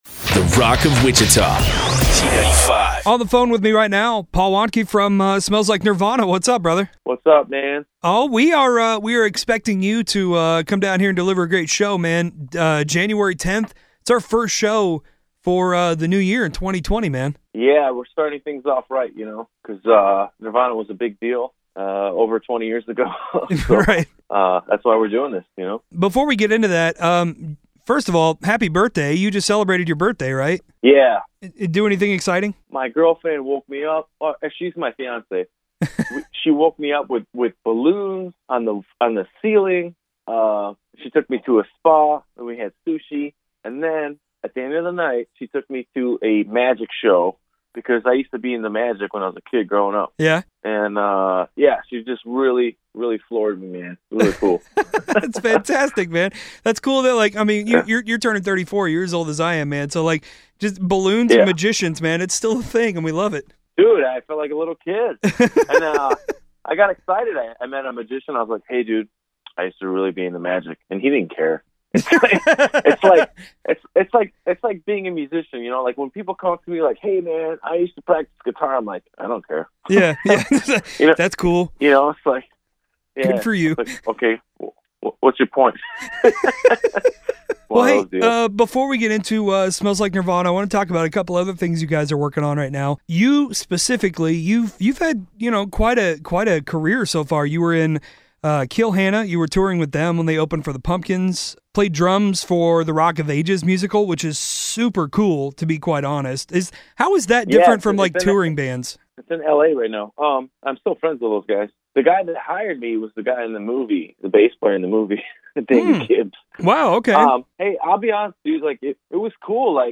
ON THE PHONE